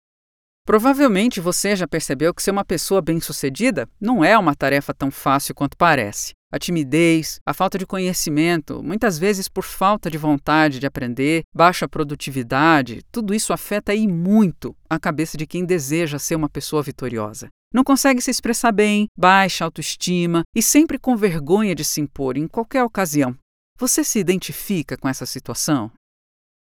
Sprechprobe: eLearning (Muttersprache):
Woman's voice, with peculiar and differentiated timbre. Voice that conveys credibility and confidence in a natural way. Neutral accent, which suits the client's needs and requirements.